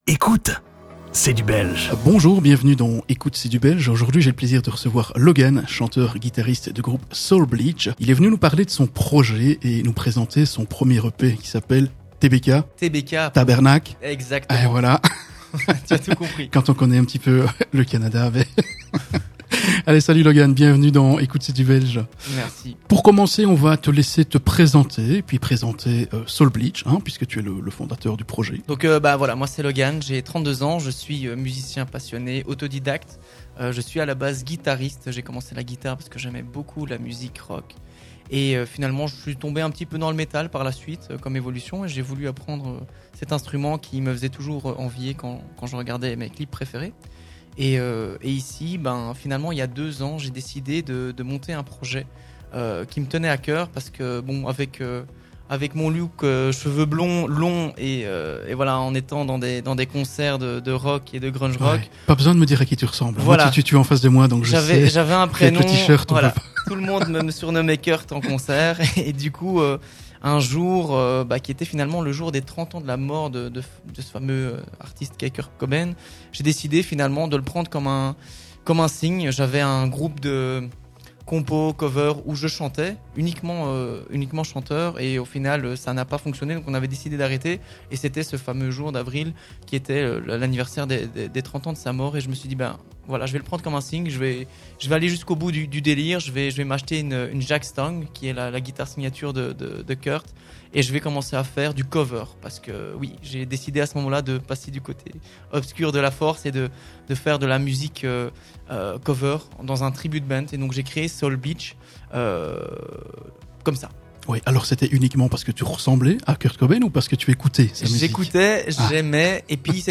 Le grunge se caractérise par des guitares fortement saturées et des paroles exprimant souvent des angoisses existentielles et une certaine apathie.
L'interview